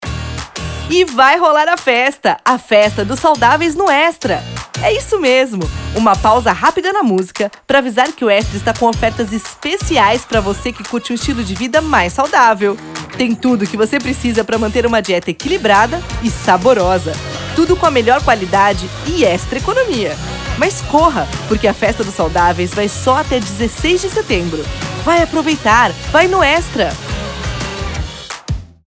Feminino
SUPERMERCADO EXTRA (varejo)
Voz Varejo 00:28